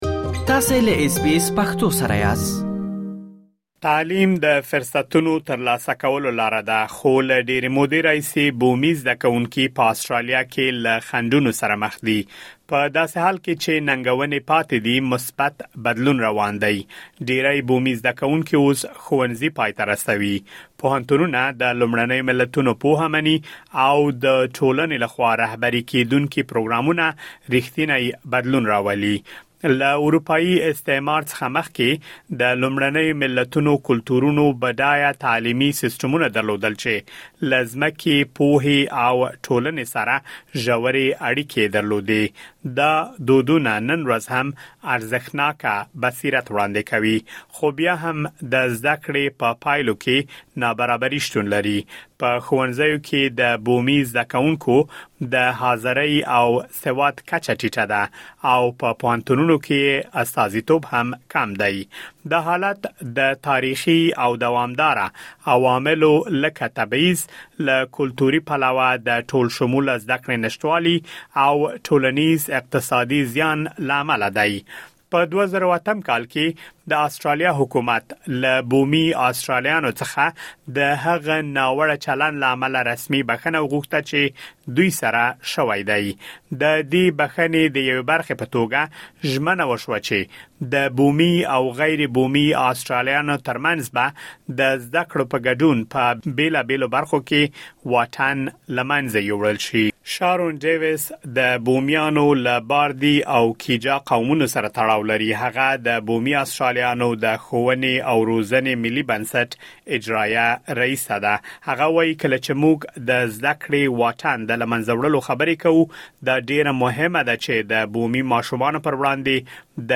ډیری بومي زده کونکو اوس ښوونځي پای ته رسوي، پوهنتونونه د لومړنیو ملتونو پوهه مني او د ټولنې له خوا رهبري کېدونکي پروګرامونه ریښتینی بدلون راولي. مهرباني وکړئ لا ډېر معلومات په رپوټ کې واورئ.